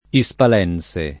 [ i S pal $ n S e ]